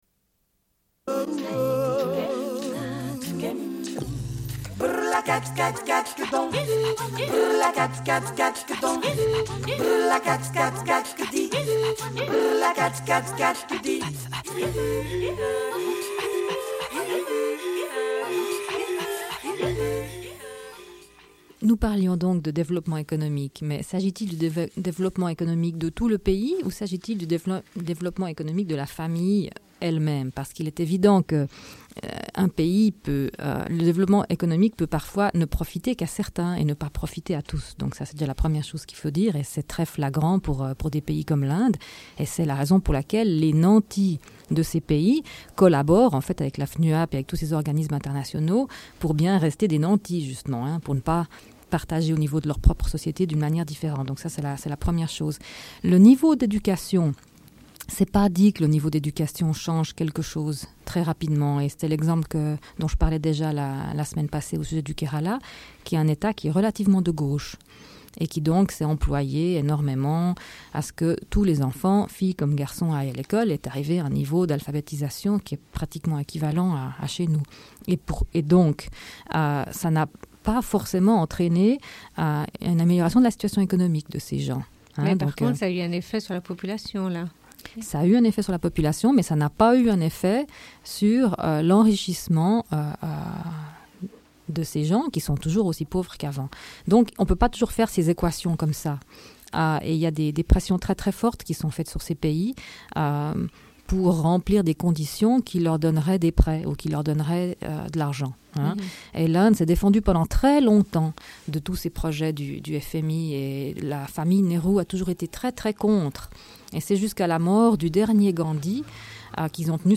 Une cassette audio, face B28:51